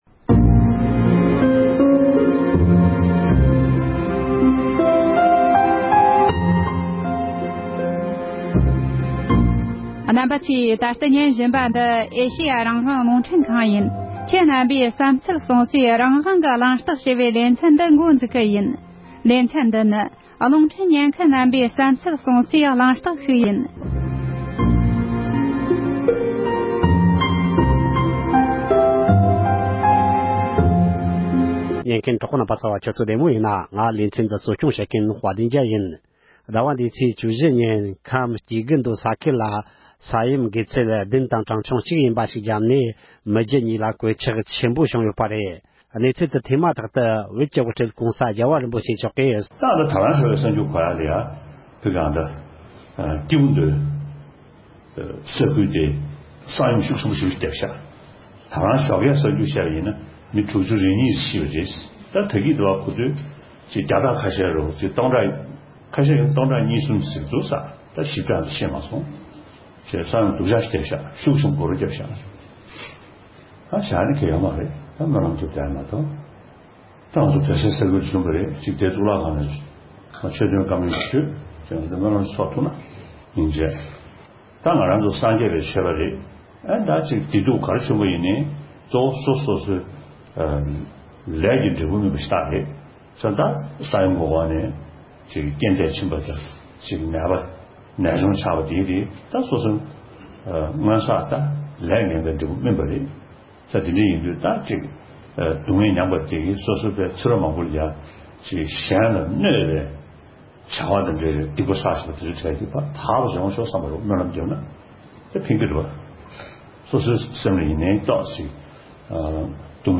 ཉེ་ཆར་བོད་ཁམས་སྐྱེ་རྒུ་མདོ་རུ་ས་ཡོམ་བརྒྱབ་ནས་མི་རྒྱུ་གཉིས་ཀ་ལ་གོད་ཆགས་ཕོག་ཡོད་པའི་སྐོར་གླེང་མོལ།